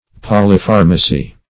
Search Result for " polypharmacy" : The Collaborative International Dictionary of English v.0.48: Polypharmacy \Pol`y*phar"ma*cy\, n. [Poly- + Gr.
polypharmacy.mp3